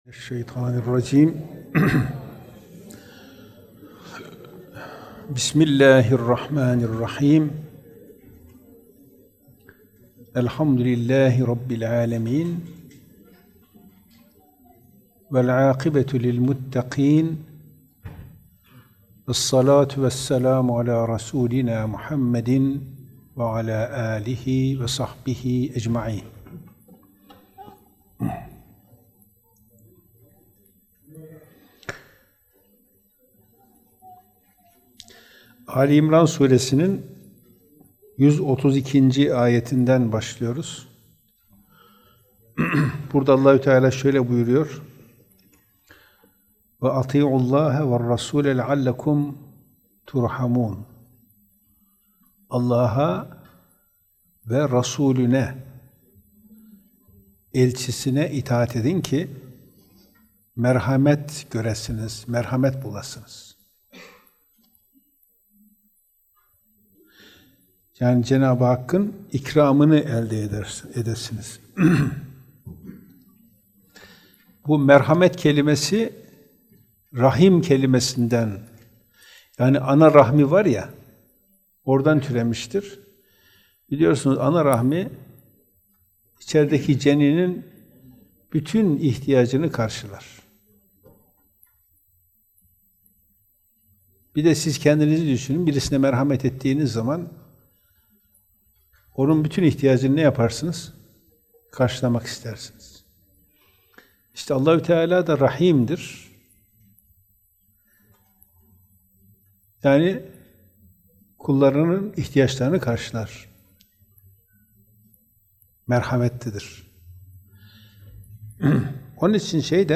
Gösterim: 3.842 görüntülenme Kur'an Sohbetleri Etiketleri: al-i imran suresi 132-136. ayetler > kuran sohbetleri Euzübillahimineşşeytanirracim, bismillâhirrahmânirrahîm.